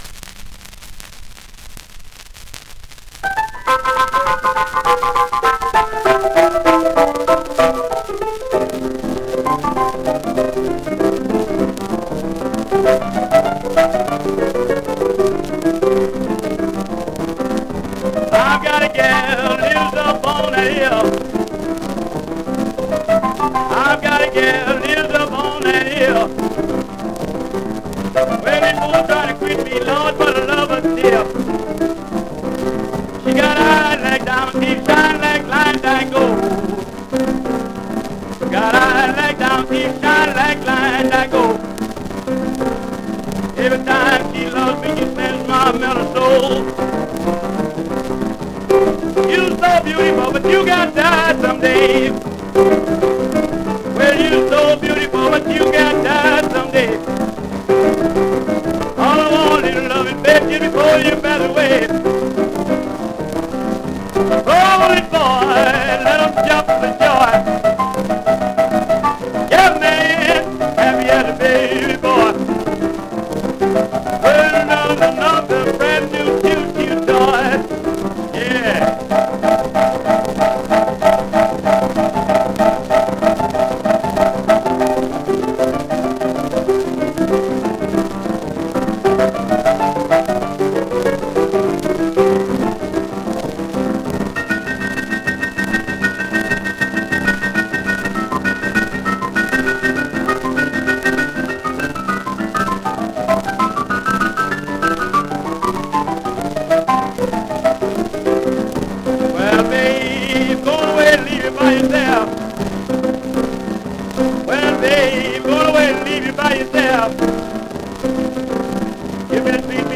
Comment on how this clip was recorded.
1 disco : 78 rpm ; 25 cm Intérpretes